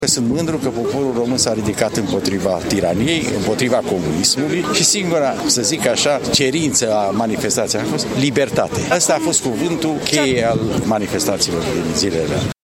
Aproximativ 200 de oameni au participat la momentul solemn care a avut loc la Cimitirul Eroilor din centrul Brașovului.